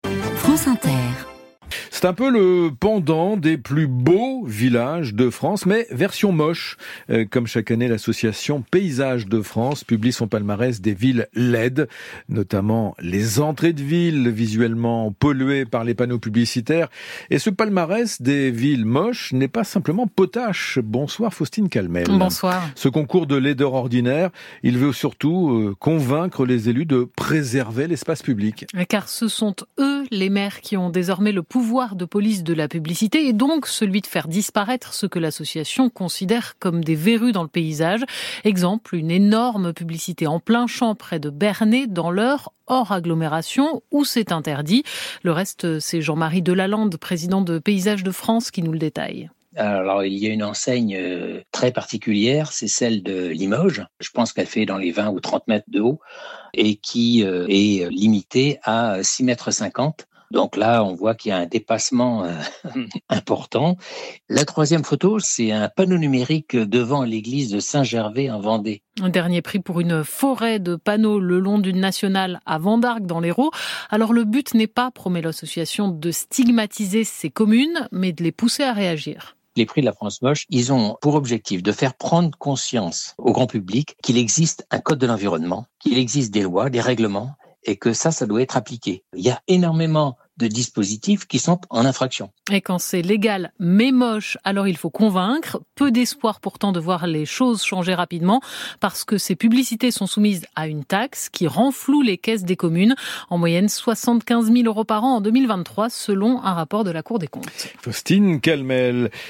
Les sites les plus moches de France : interview